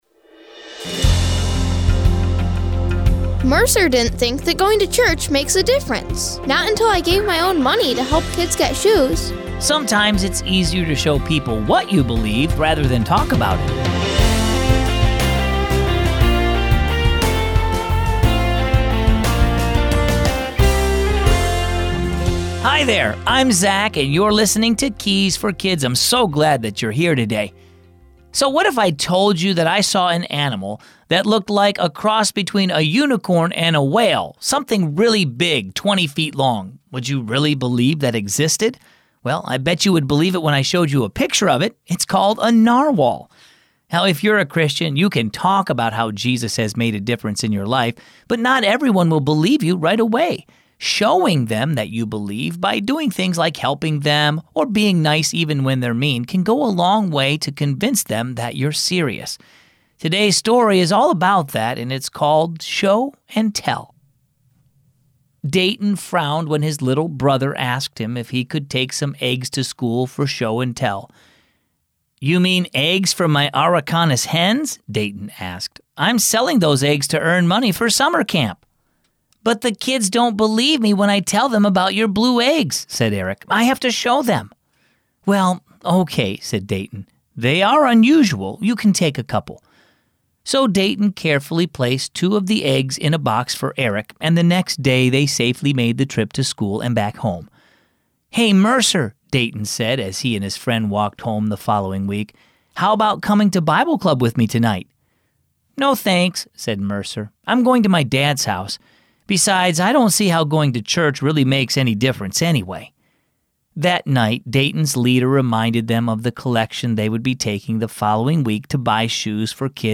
Keys for Kids - daily devotions and Bible stories for kids and families